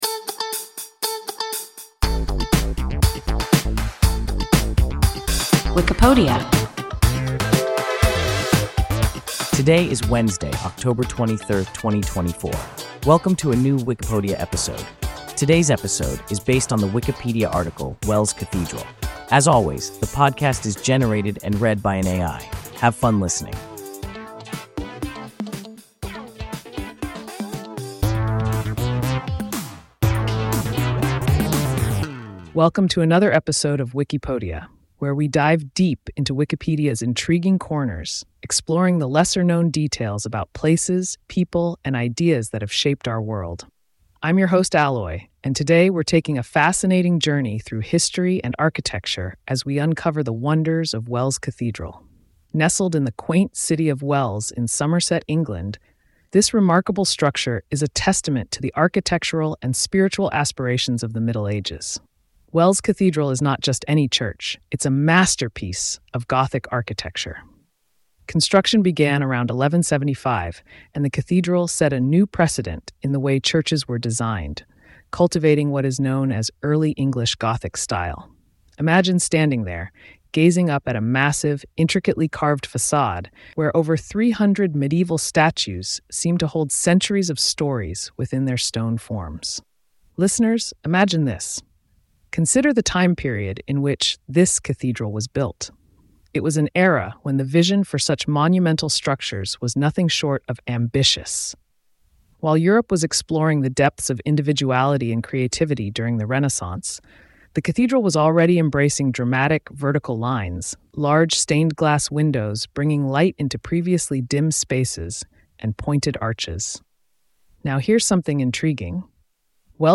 Wells Cathedral – WIKIPODIA – ein KI Podcast